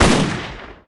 shotgun_fire_01.ogg